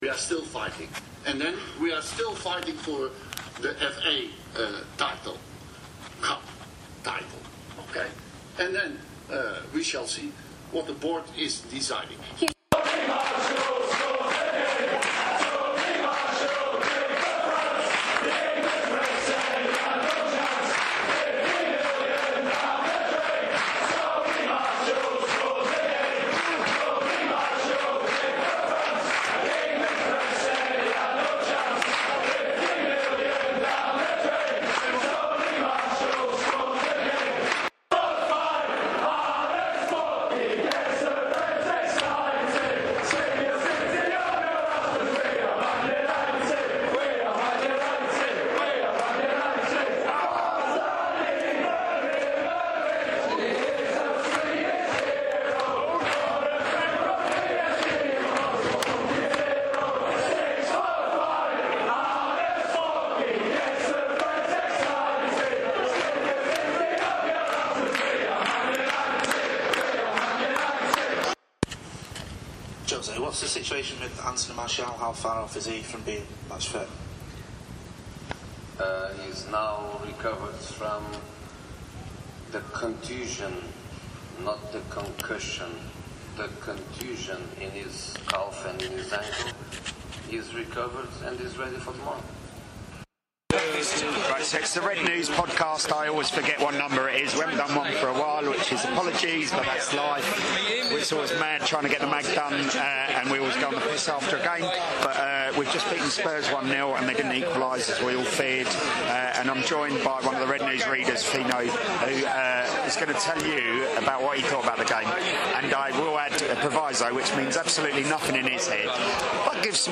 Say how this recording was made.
The independent, satirical Manchester United supporters' fanzine - for adults only, contains expletives - returns with waffle and bad sound. Here we talk after the 1-0 win over Spurs with a Red who was a semi pro player in the League of Ireland and reflect on 2016.